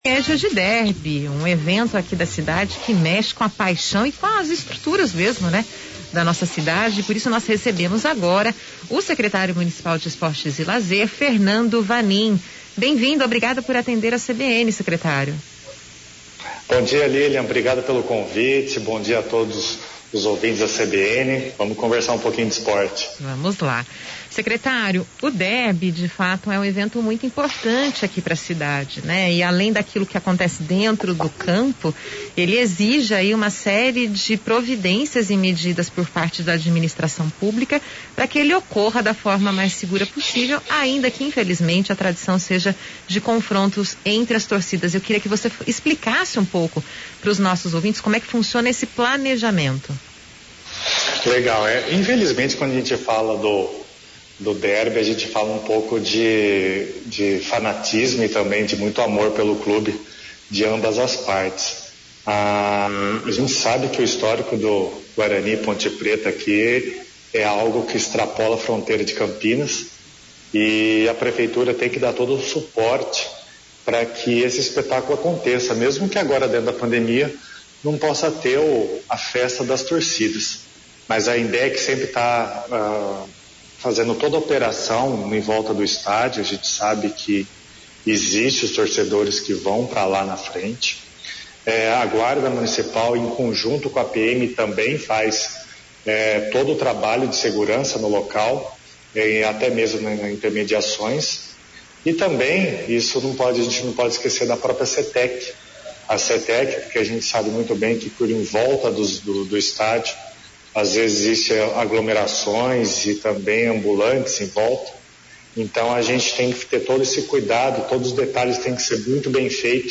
No programa, uma entrevista sobre a importância histórica do confronto e a vitrine que a rivalidade proporciona para a cidade. No CBN Campinas, a participação do secretário de esportes e lazer, Fernando Vanin.